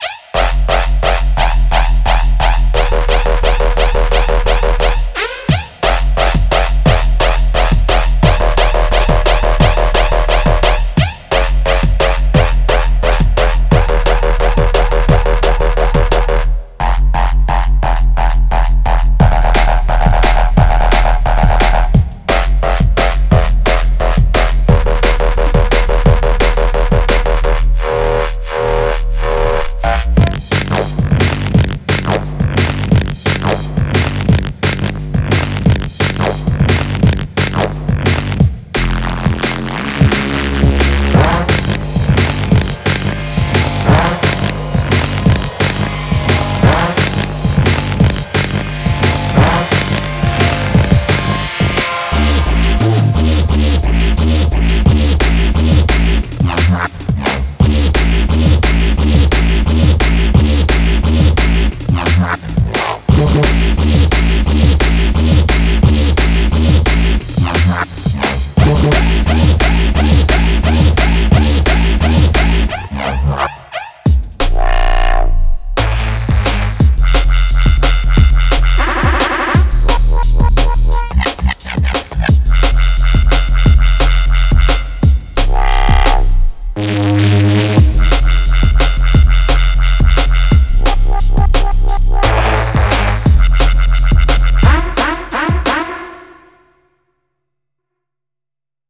We included a wide range of new Drum Loops, genre-defining Drum Samples, sustained Reese Bass, One-shots, Melodic riffs, Synth hooks, Stabs, and Fx sounds.